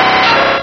Cri_0123_DP.ogg